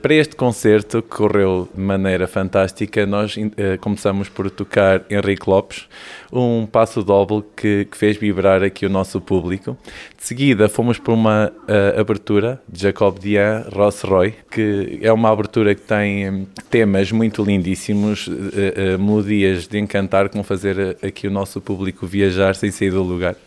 ntrevistas